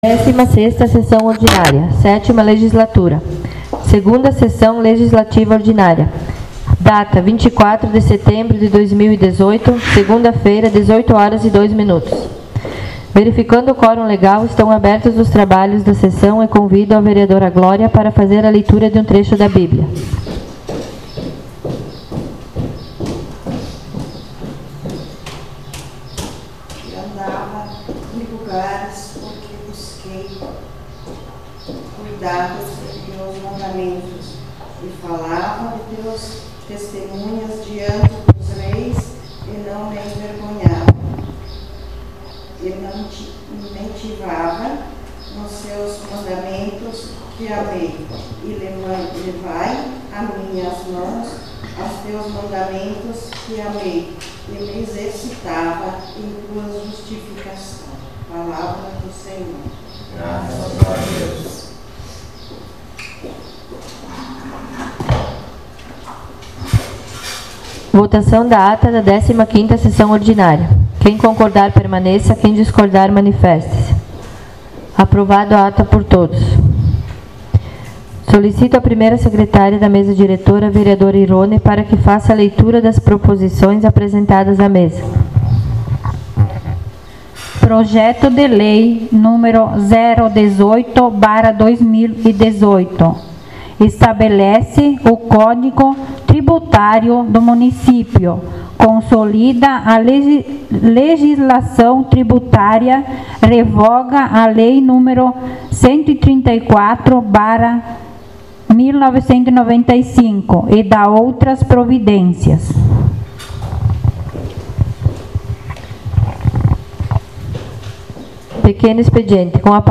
16ª Sessão ordinária 24.09.18